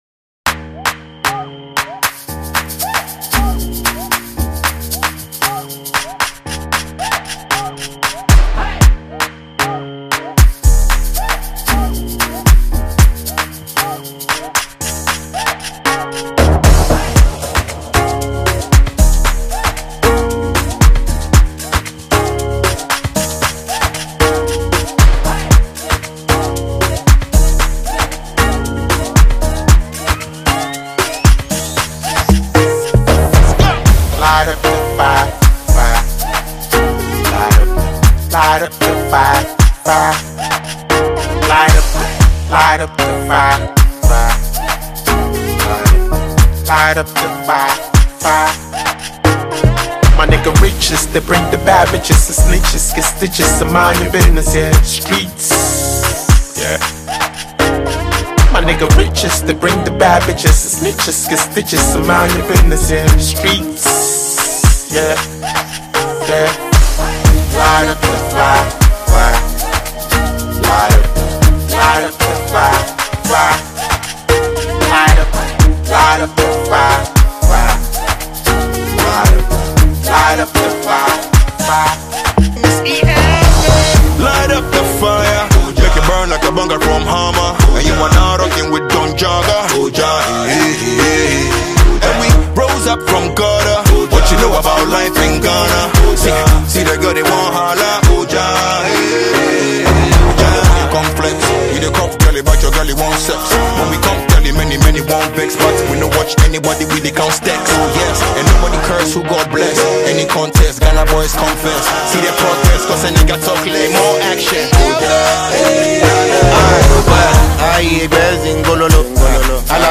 Ghana Music
In a historic moment for Ghanaian Hip-Hop